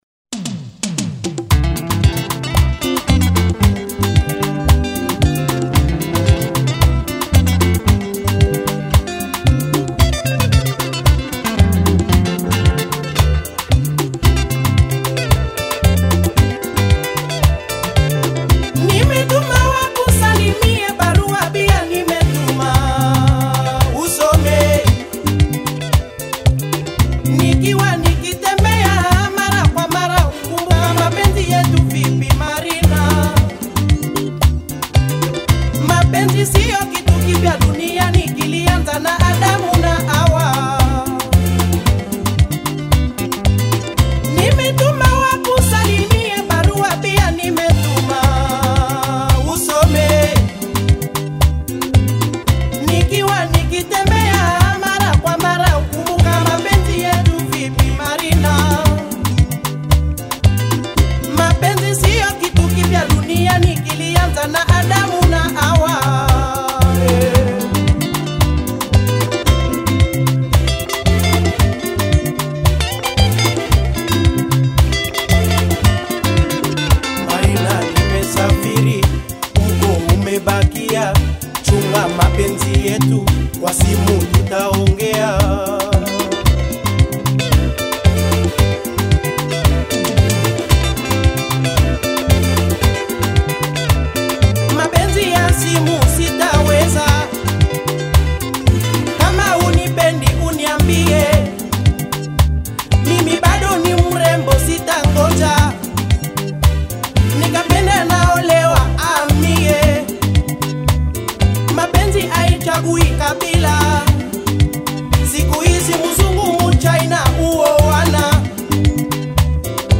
crisp production